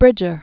(brĭjər), James 1804-1881.